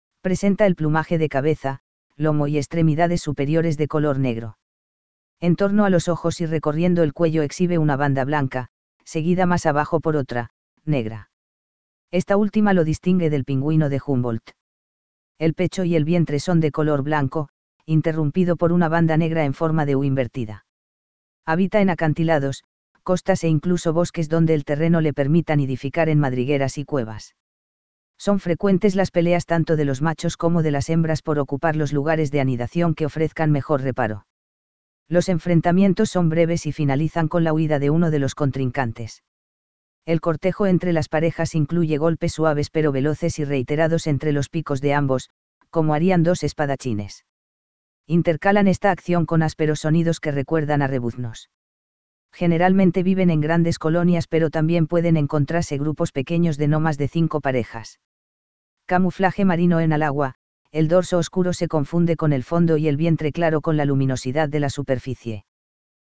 Spheniscus magellanicus - Pingüino de magallanes
Intercalan esta acción con ásperos sonidos que recuerdan a rebuznos.
Pinguinomagallanes.mp3